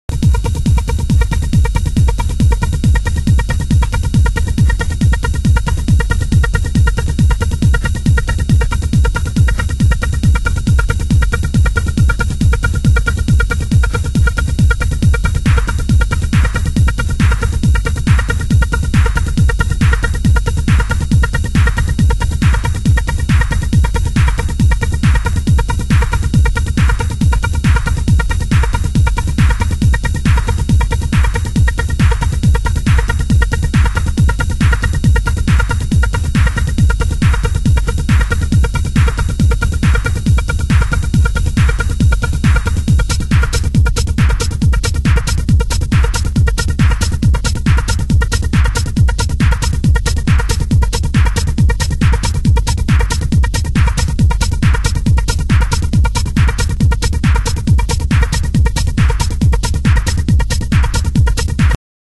盤質：少しチリパチノイズ有/軽いスレ傷有